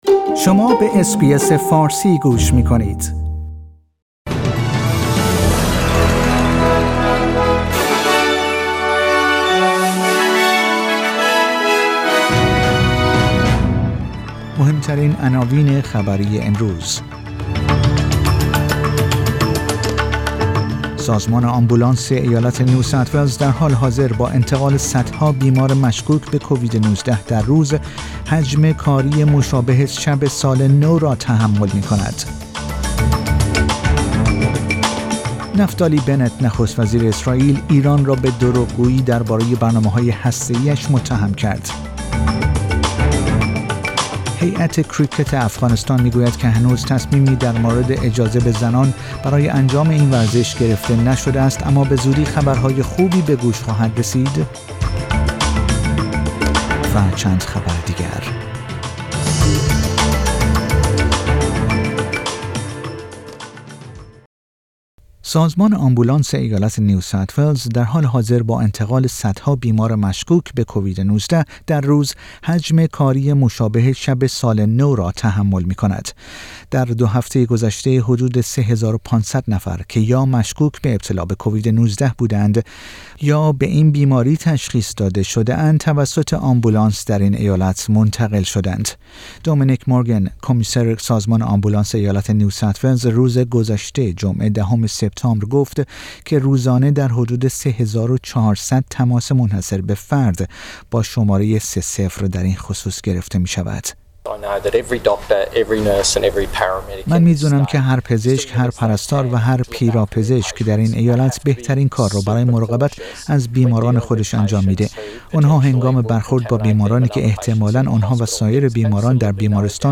پادکست خبری آخر هفته اس بی اس فارسی